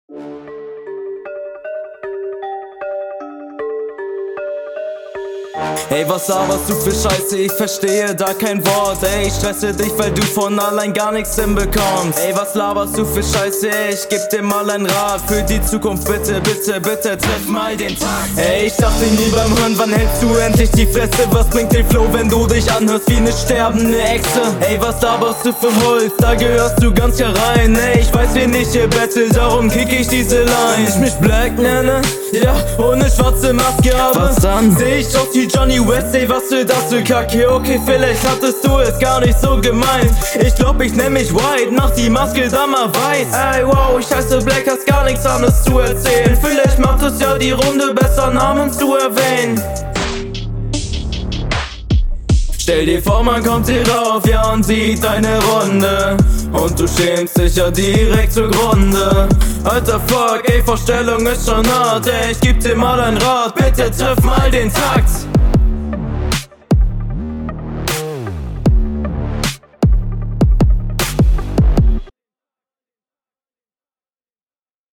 Stimmeneinsatz und Flow ist hier besser als dein Gegner.